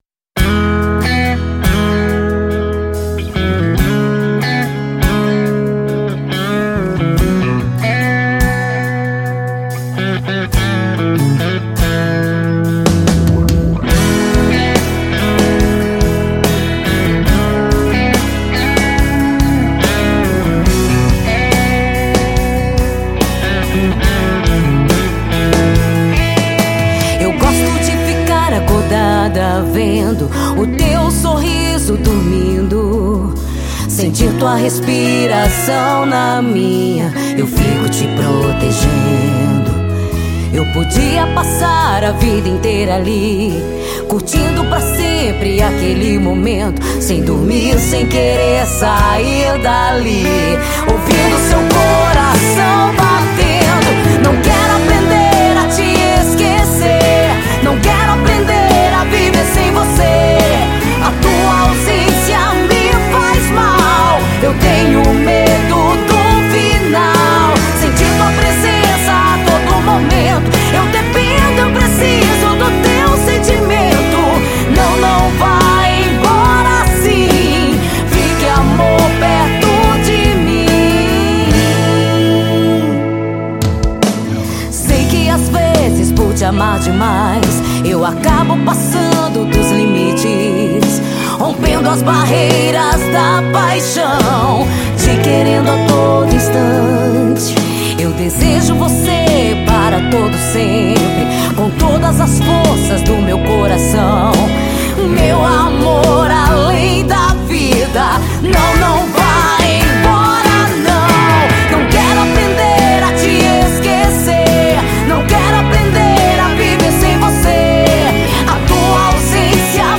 EstiloPop
Cidade/EstadoSinop / MT